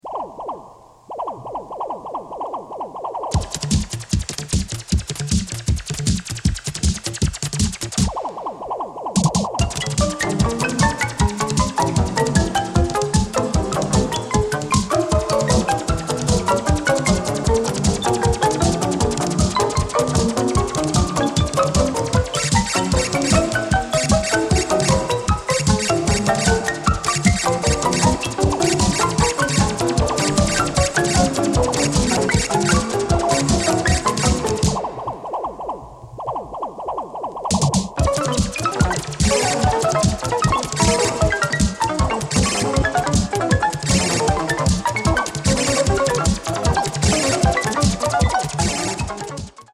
awesome oddball Slovakian Techno banger